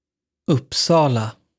uttal(fil)) (äldre stavning Upsala) är en tätort i Uppland, centralort i Uppsala kommun och residensstad för Uppsala län.